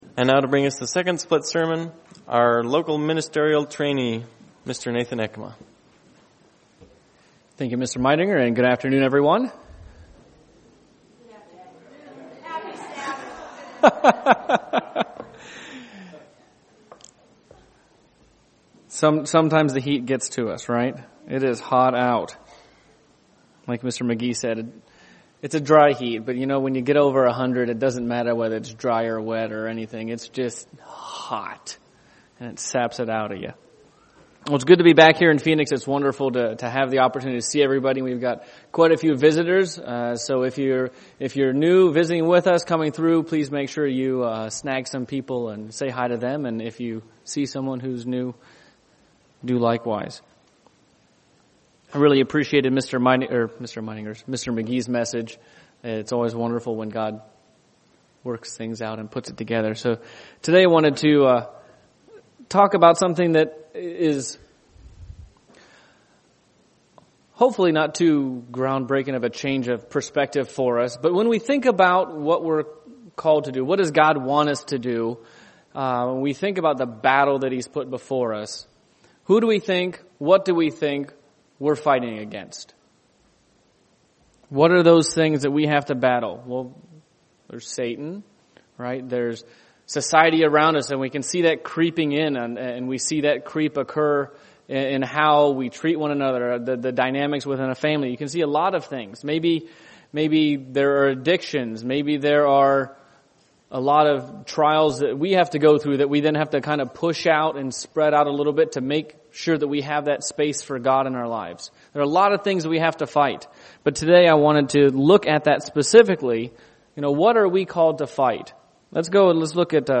Given in Phoenix East, AZ
UCG Sermon Studying the bible?